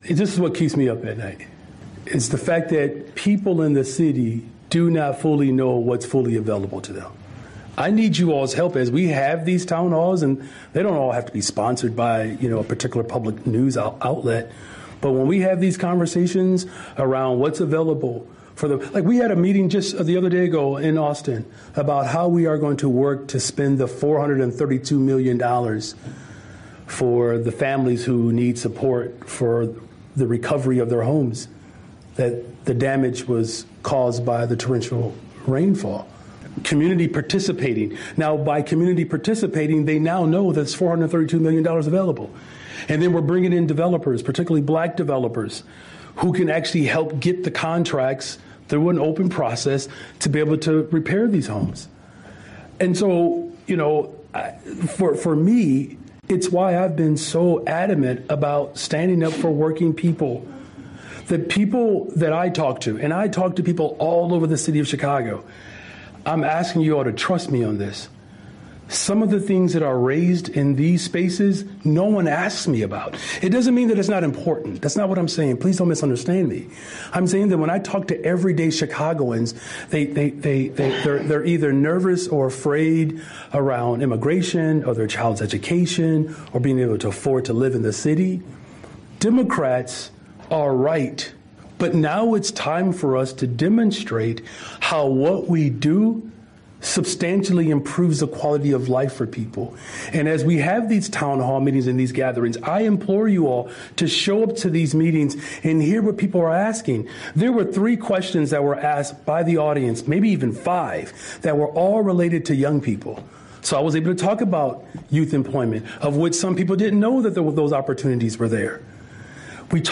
At a February 4 press conference, Mayor Brandon Johnson urged assembled reporters to "tell the story of the people" in order to "make sure this country gets back on track."
The mayor's remarks came in reply to a reporter's question about what keeps him up at night.